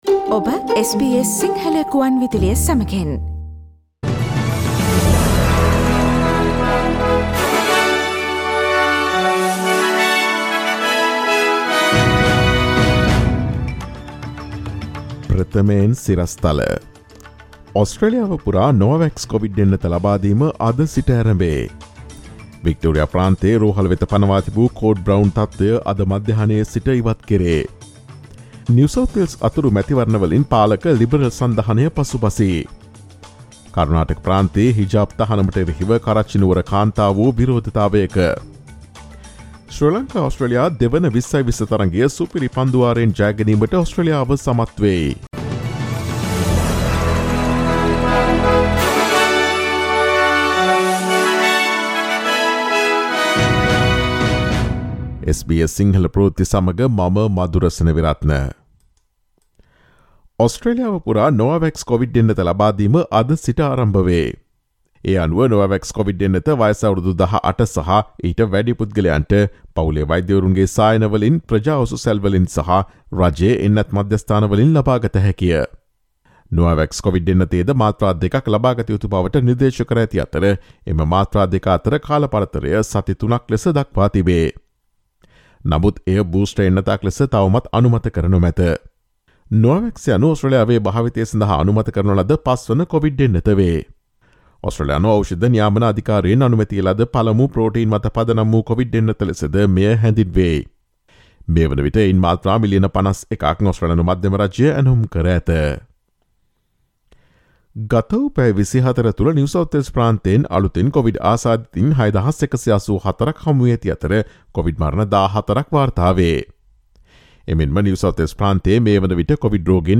පෙබ 14 දා SBS සිංහල ප්‍රවෘත්ති: වික්ටෝරියා ප්‍රාන්තයේ රෝහල් වෙත පනවා තිබූ code brown තත්ත්වය අද සිට ඉවතට
ඔස්ට්‍රේලියාවේ නවතම පුවත් මෙන්ම විදෙස් පුවත් සහ ක්‍රීඩා පුවත් රැගත් SBS සිංහල සේවයේ 2022 පෙබරවාරි 14 වන දා සඳුදා වැඩසටහනේ ප්‍රවෘත්ති ප්‍රකාශයට සවන් දීමට ඉහත ඡායාරූපය මත ඇති speaker සලකුණ මත click කරන්න.